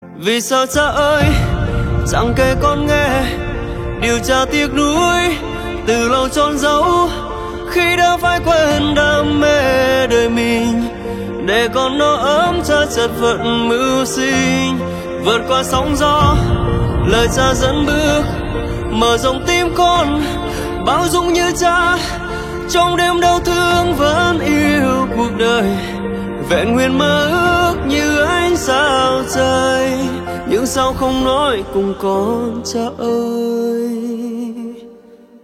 Nhạc Chuông Nhạc Phim